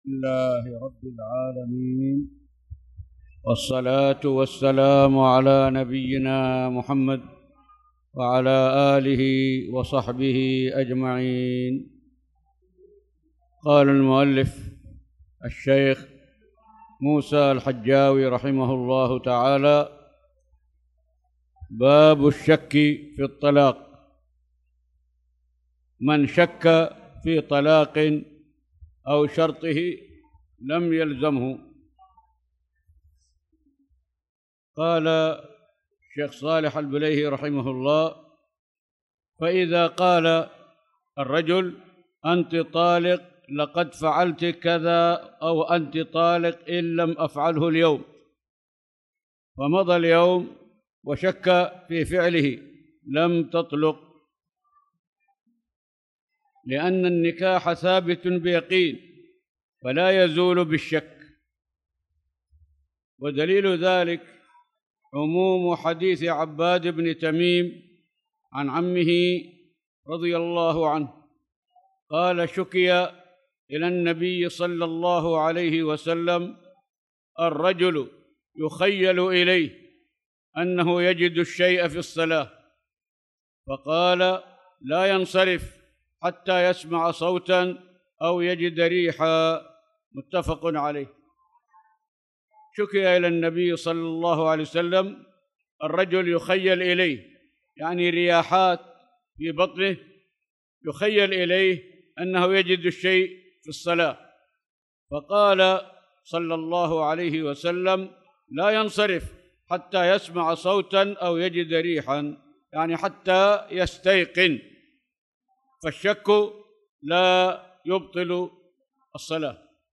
تاريخ النشر ٢٧ ذو القعدة ١٤٣٧ هـ المكان: المسجد الحرام الشيخ